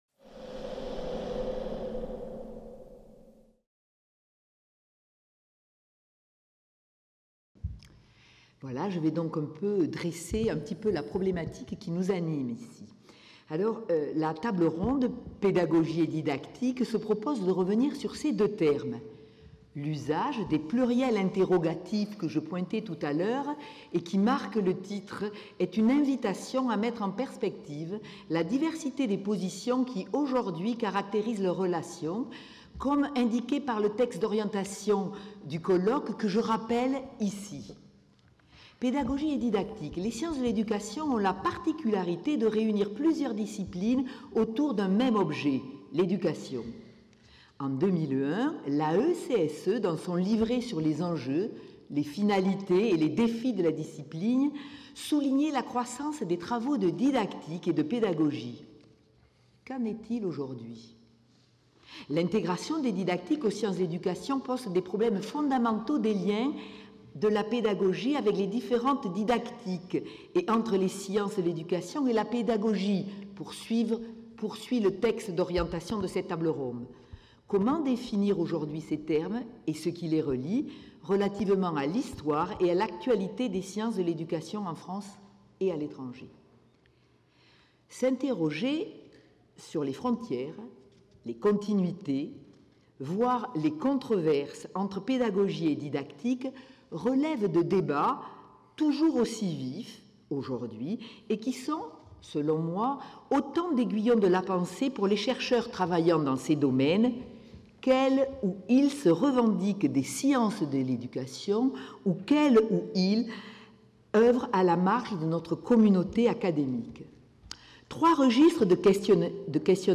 50 ans de sciences de l'éducation - 03 Table ronde : Pédagogie(s), didactique(s) | Canal U
(Amphithéâtre Pierre Daure)